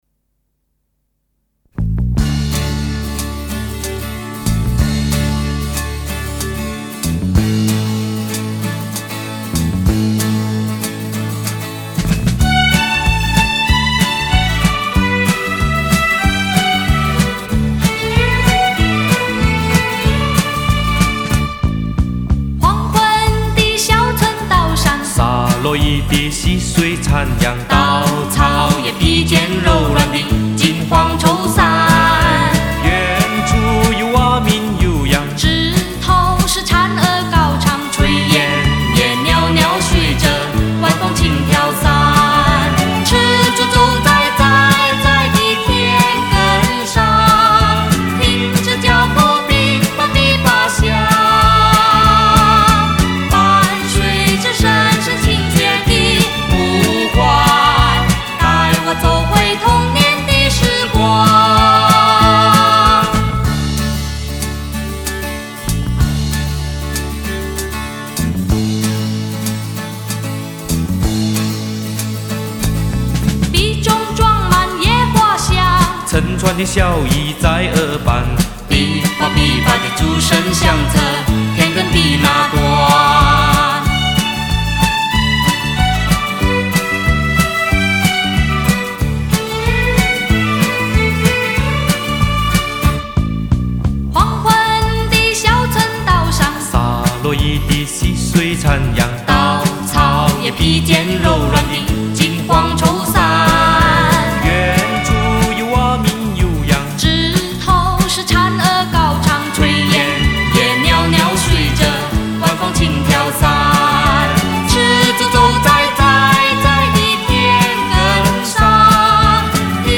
（MP3/192K/低品质）